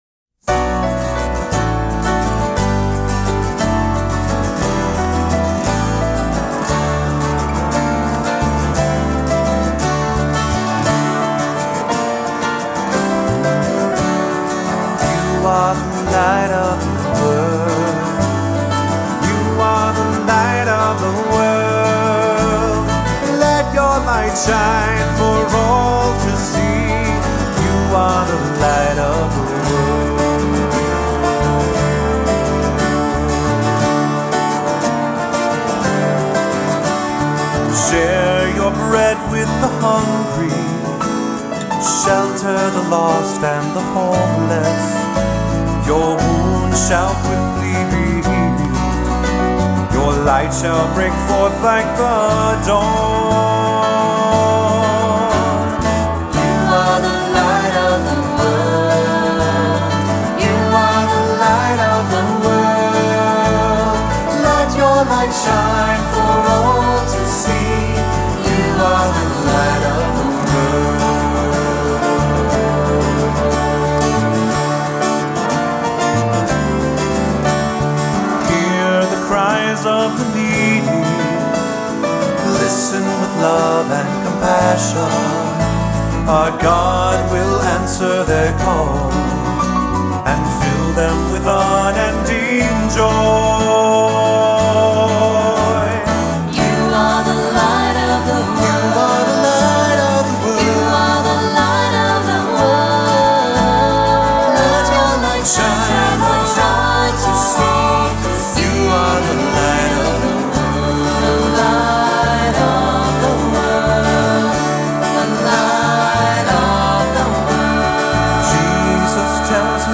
Voicing: 3-part Choir, assembly, cantor